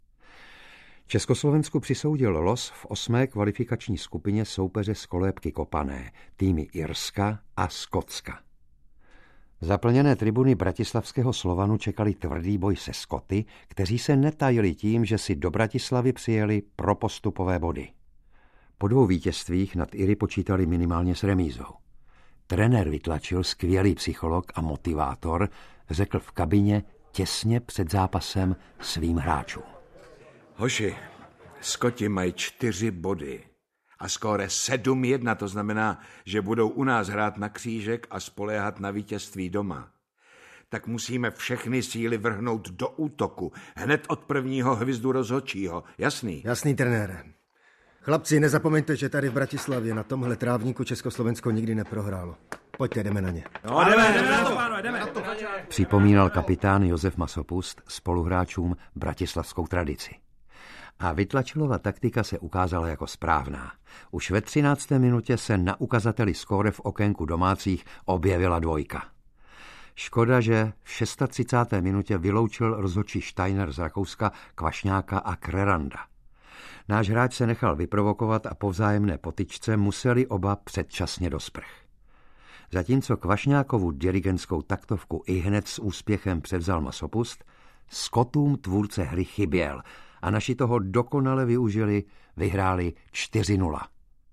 Stříbrní Chilané aneb Legenda žije audiokniha
Ukázka z knihy
Slavný příběh československého fotbalu - zisk stříbrného poháru na mistrovství světa v Chile v roce 1962 - ožívá na této audioknize podle námětu Jiřího Hory: v dramatizaci Jana Jiráně Českou uličkou ke stříbru a v dokumentárním zpracování Jana Kaliby Chilané se vracejí. Nejen o vztahu s Pelém mluví Josef Masopust, hovoří také Adolf Scherer, Josef Kadraba, Rudolf Vytlačil, Amarildo, Djalma Santos, Mario Zagallo a další. Poznáte životní osudy "stříbrných Chilanů" a prožijete jejich dobrodružný návrat do Jižní Ameriky po padesáti letech!